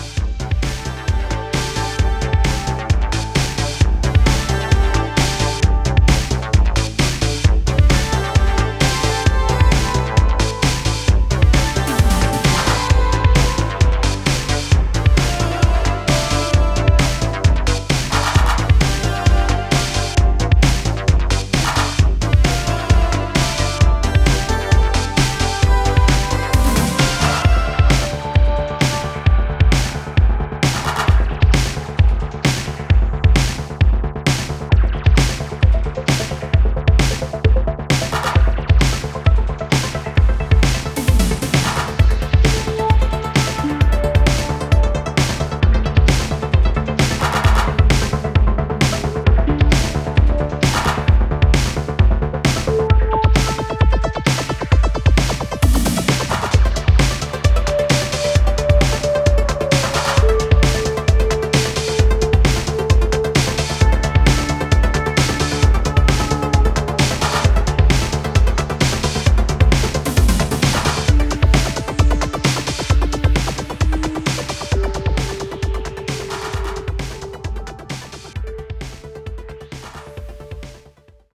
クラウトロック等のヨーロピアンなロマンチズムを孕んだエレクトロ